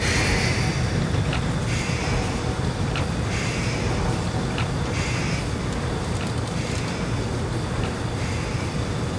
1 channel
00033_Sound_WAMBIENT.mp3